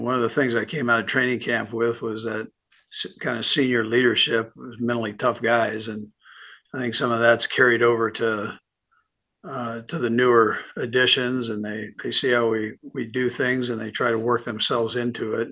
Andy Reid press conference